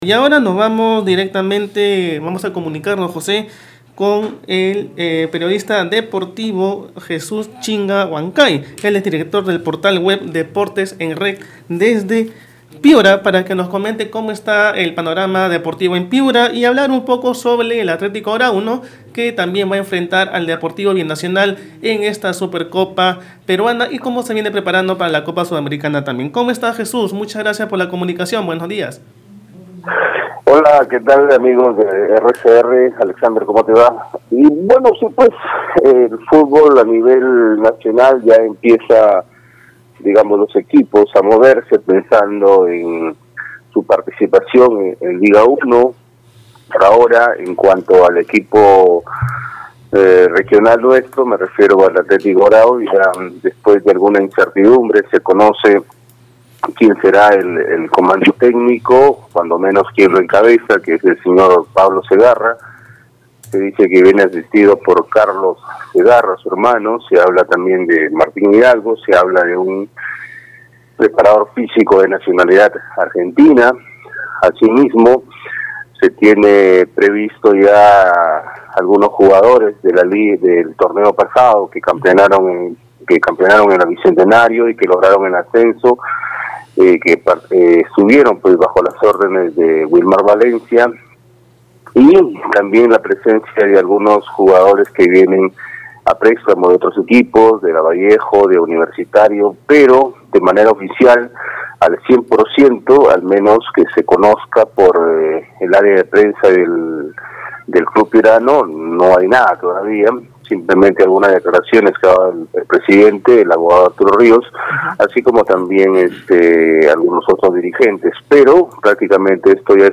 El periodista deportivo